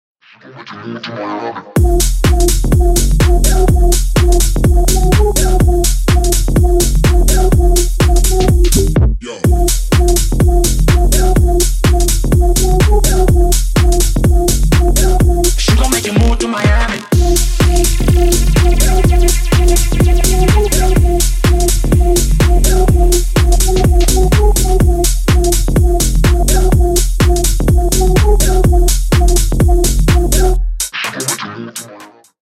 Клубные Рингтоны
Рингтоны Ремиксы
Рингтоны Электроника